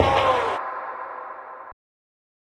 Vox (Metro Bang).wav